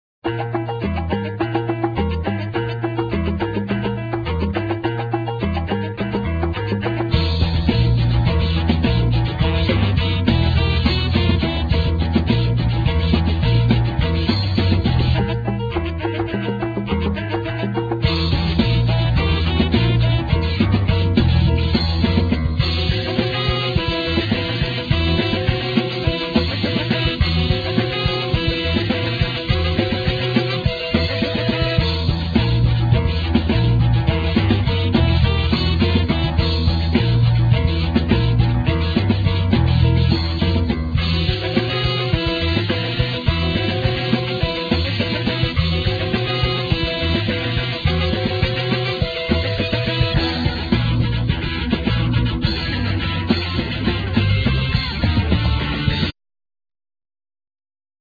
Guitar,Keyboards,Percussion,Timpani,Tublar bells
Bass,Vocal
Drums,Vocal